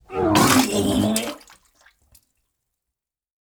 Free vomiting sound effects in wav and mp3 formats
Throwing up sound effect
Vomiting 02
vomiting-02.wav